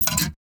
UIClick_Menu Back Metallic Double Click 03.wav